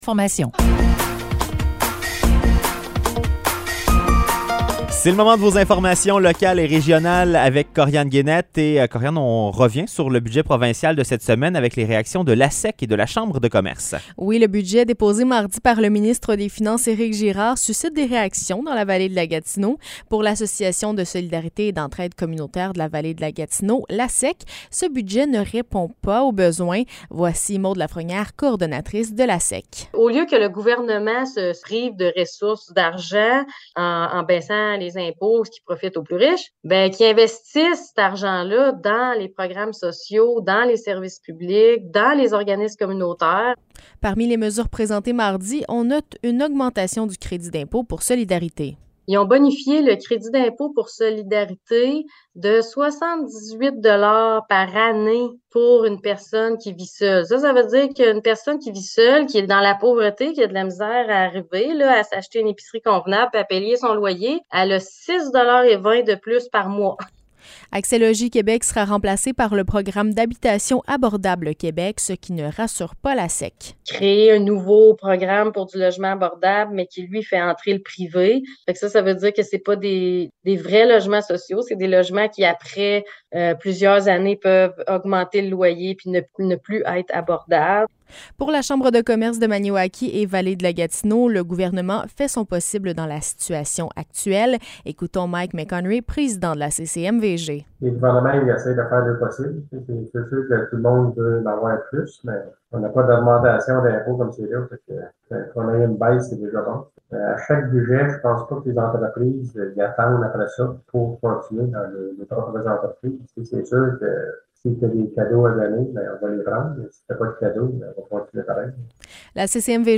Nouvelles locales - 24 mars 2023 - 16 h